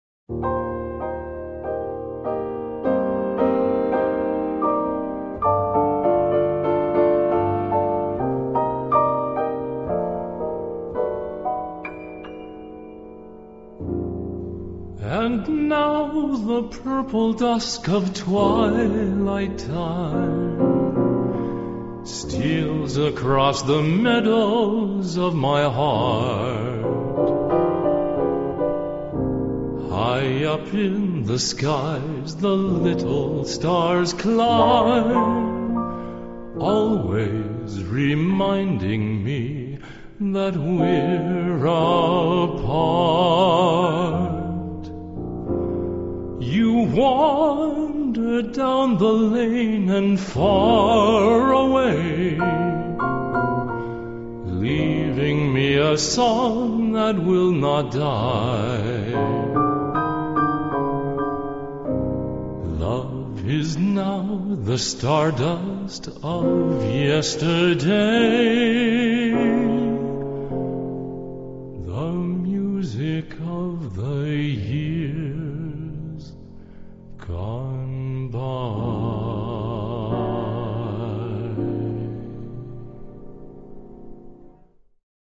a gentle voice
baritone
light, velvety, and sweet without ever being cloying